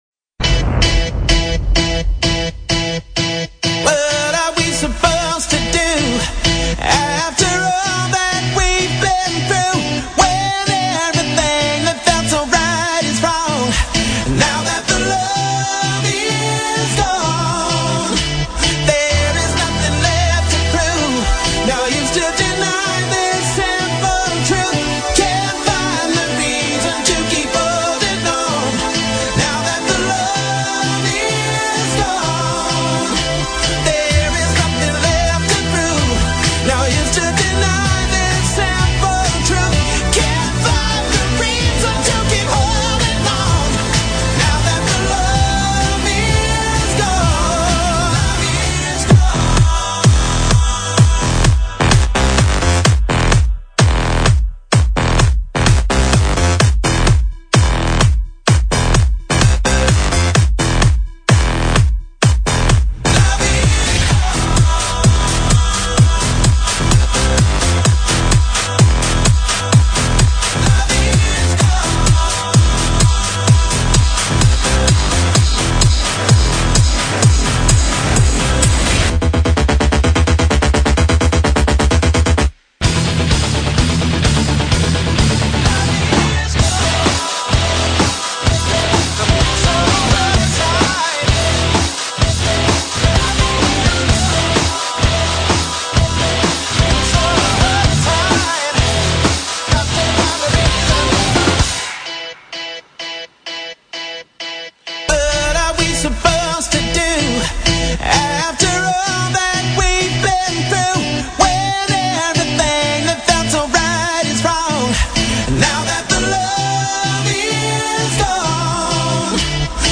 love_is_gone_electrometal1.mp3